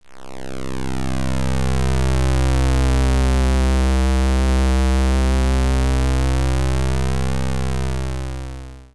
synth15.wav